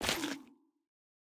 sounds / block / sculk / step1.ogg
step1.ogg